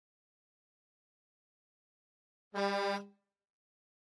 Arquivo:Buzinadecaminhao-audio.ogg